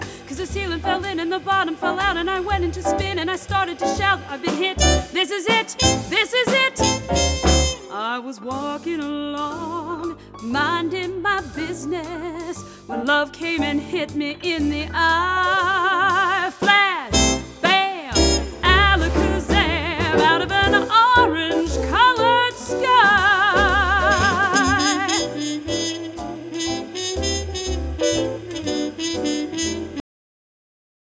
Sample These Song Snippets from the Album